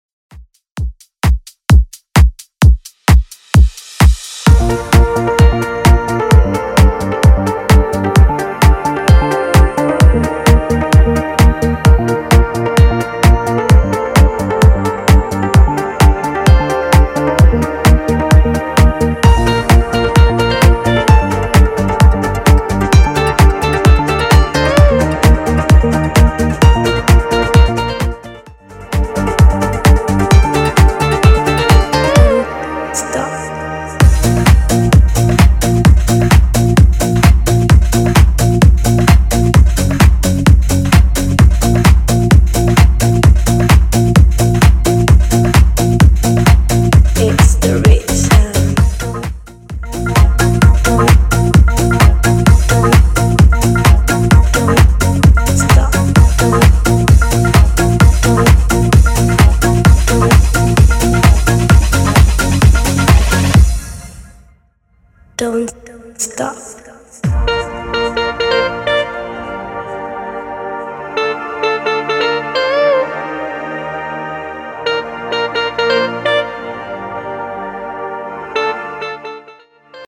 Genre: 80's Version: Clean BPM: 130